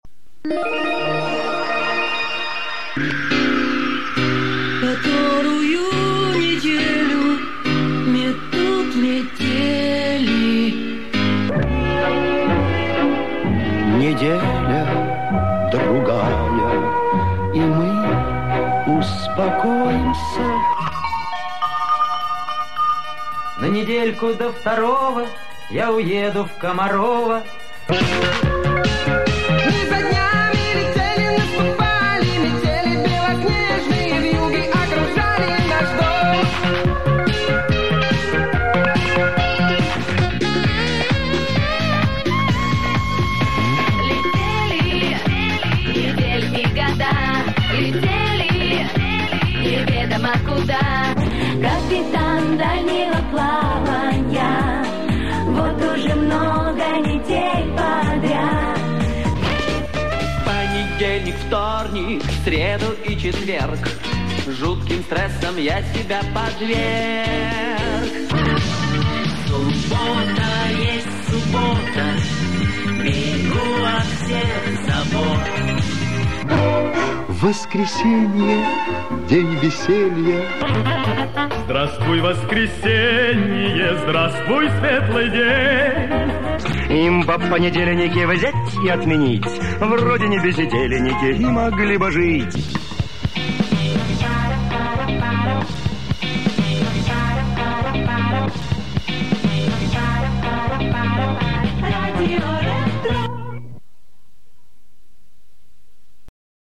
Предлагаю вашему вниманию музыкальные заставки придумывал и записывал сам на магнитоле "Panasonic CT980". Оцифровка с кассет.
заставки